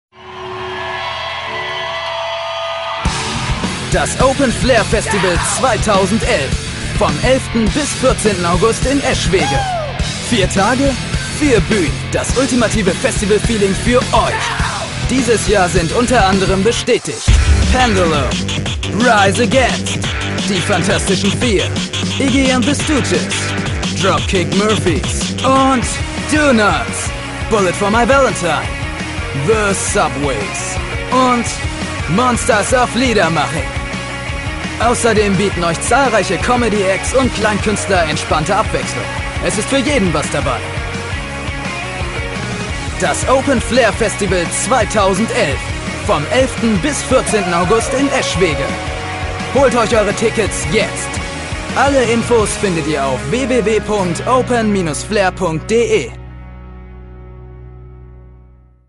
Jugendliche, dynamische, variable, markante, unverbrauchte, ausdrucksstarke, kräftige und facettenreiche Stimme.
Sprechprobe: Industrie (Muttersprache):
Young, energetic, variable, distinctive, expressive, powerful and multifarious voice.